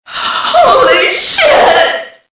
Index of /cstrike/sound/female